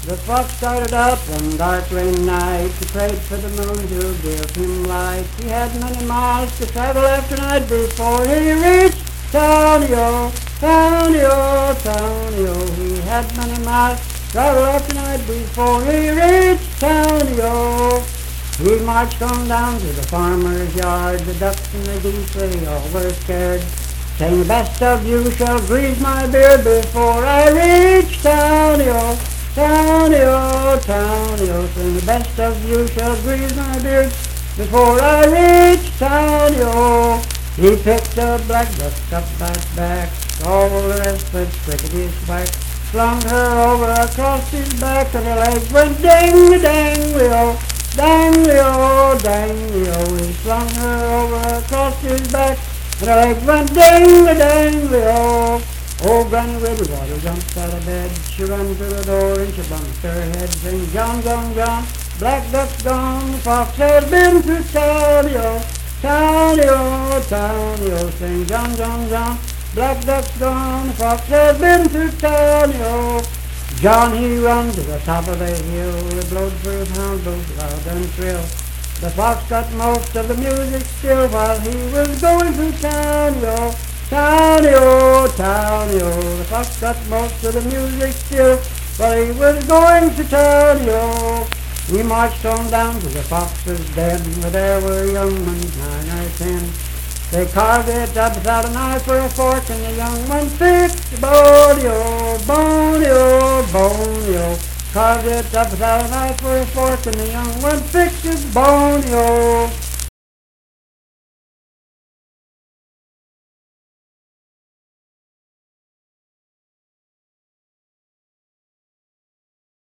Unaccompanied vocal performance
Miscellaneous--Musical
Voice (sung)
Harrison County (W. Va.)